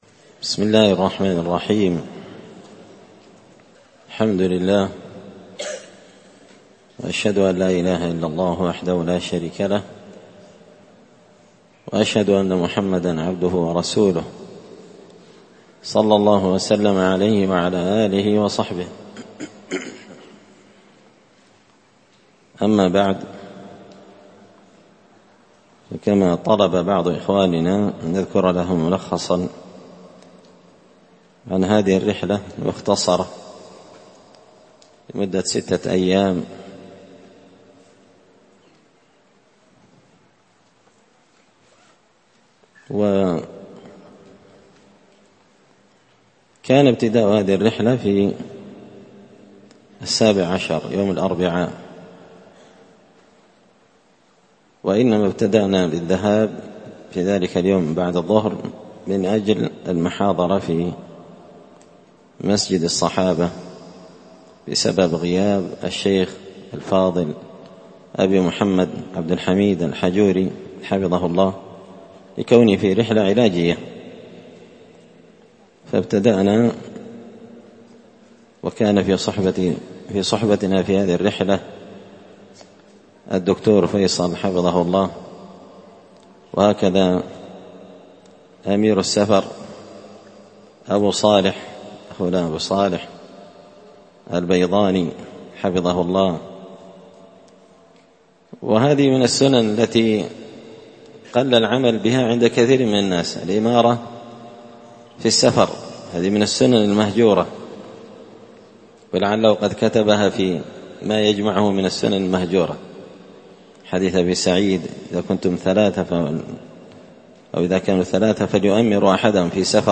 الأربعاء 24 رجب 1444 هــــ | الخطب والمحاضرات والكلمات | شارك بتعليقك | 21 المشاهدات